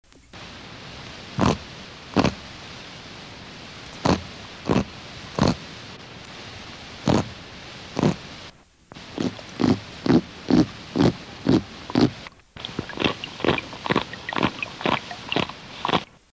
Type of sound produced feeding noise & scratchy grunts
Sound mechanism pharyngeal teeth stridulation amplified by adjacent swim bladder (similar to Haemulon album) Behavioural context weak chewing noise during competitive feeding, scratchy grunting with mild annoyance
Remark recordings of three specimens